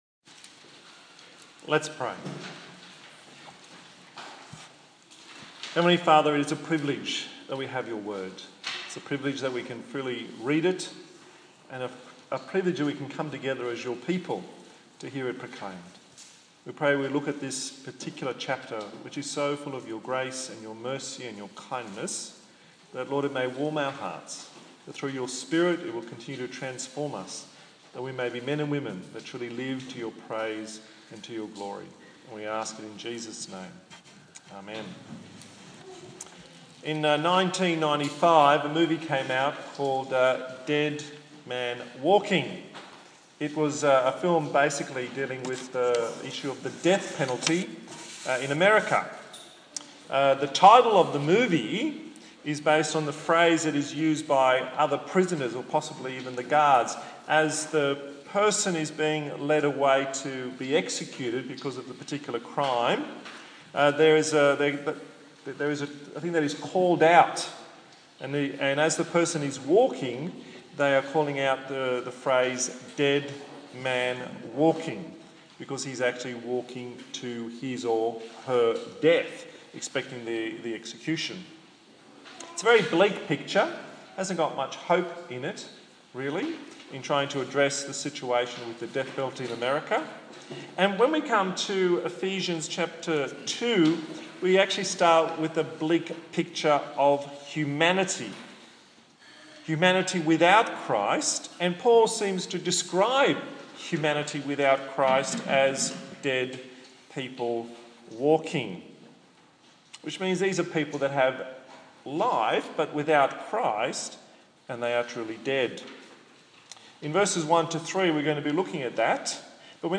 18/10/2015 Grace Changes Everything Preacher